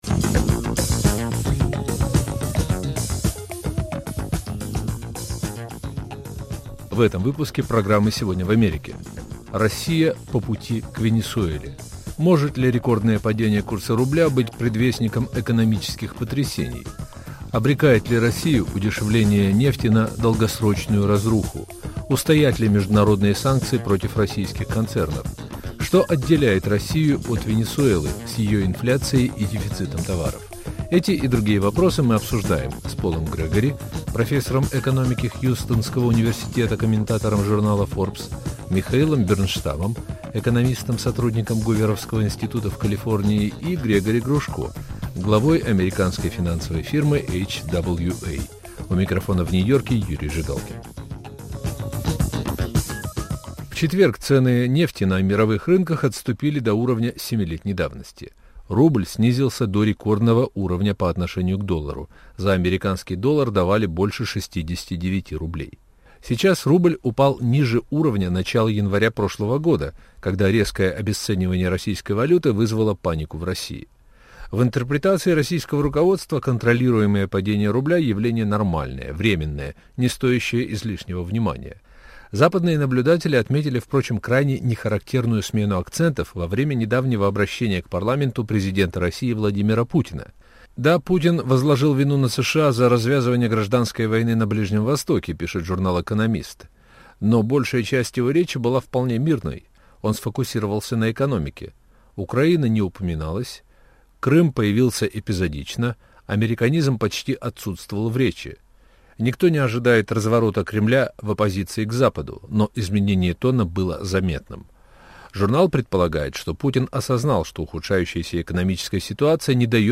Что отделяет Россию от Венесуэлы с ее инфляцией и дефицитом товаров? Обсуждают американские эксперты.